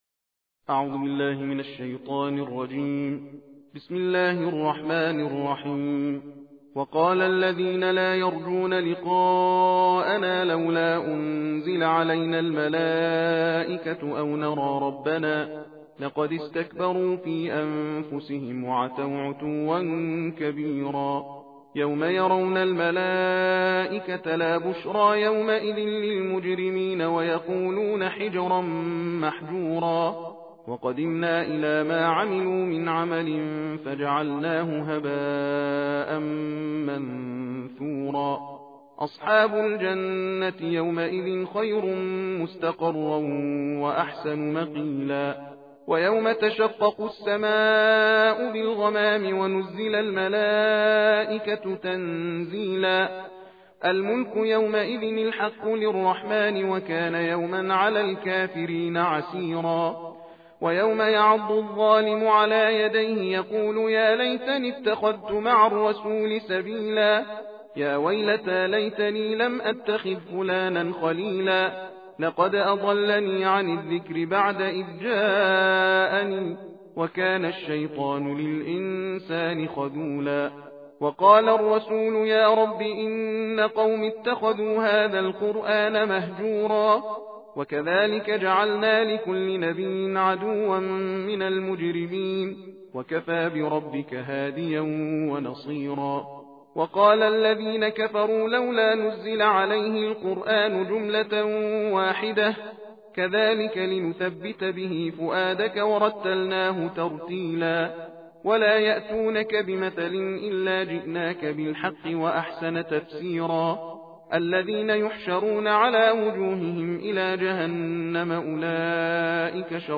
ترتیل جزء نوزدهم قرآن کریم را در ادامه به همراه متن و ترجمه می توانید دریافت نمایید.